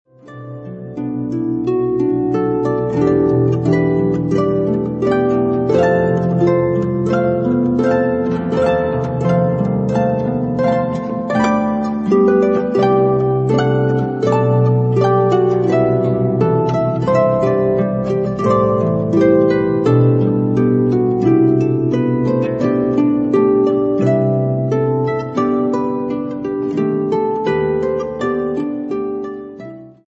beautiful harp music